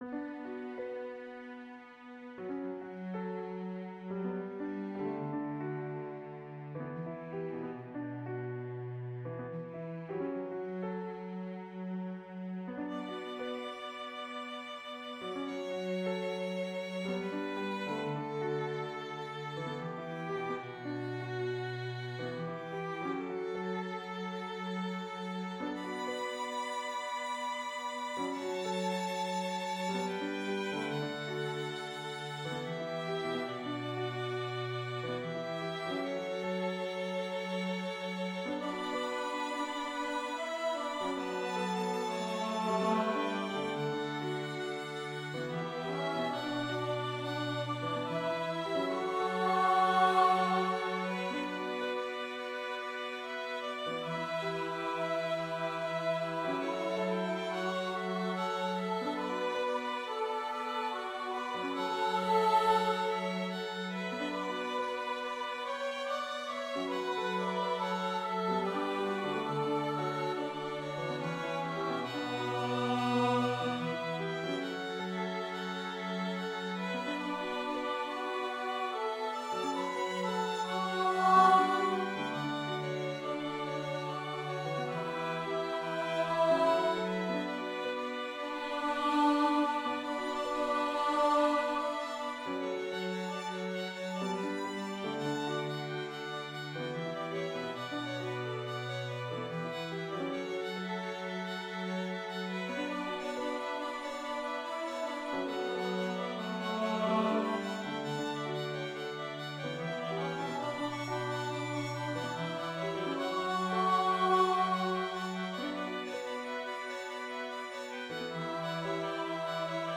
Oooooh, so haunting!!!